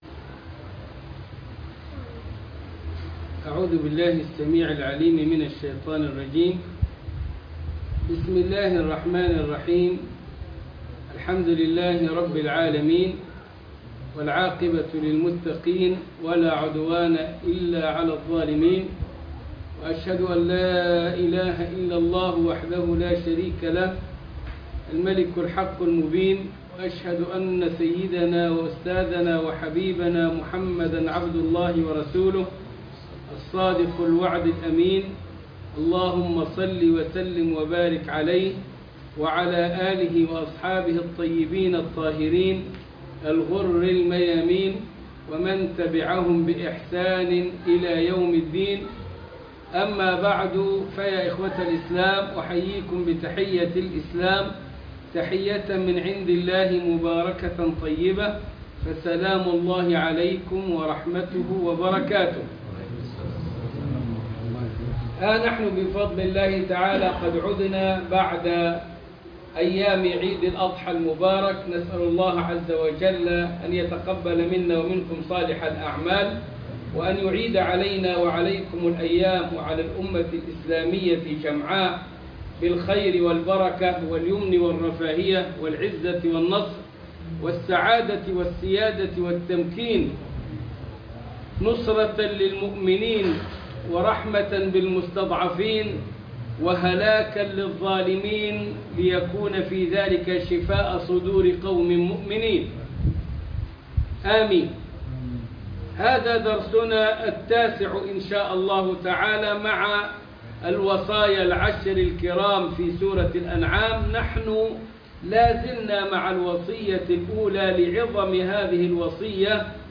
بث مباشر 9